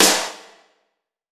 ESNARE 069.wav